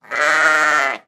Звуки баранов, овец
Одинокий звук овцы